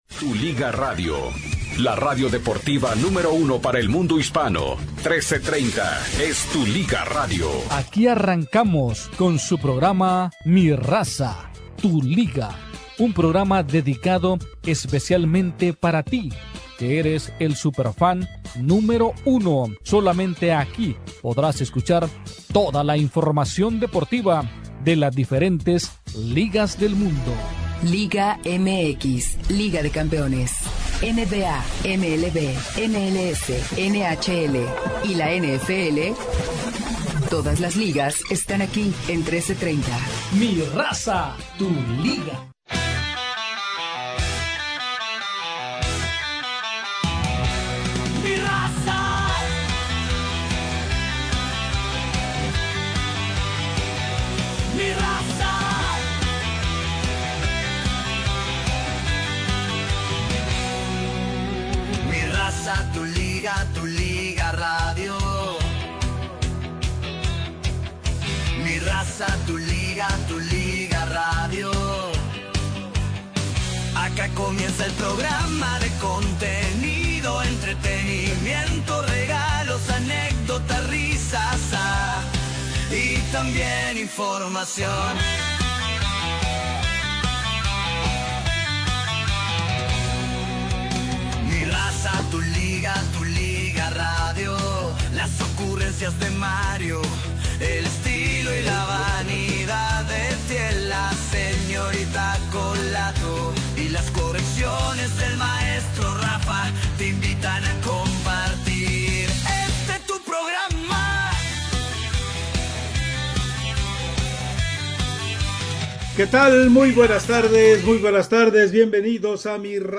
Memo Ochoa, con nuevo equipo, en una Liga más que medio pelo. como un equipo tambien de medio pelo. Hoy con entrevista a Felix Fernandez, exportero del Atlante, y de la seleccion de Mexico.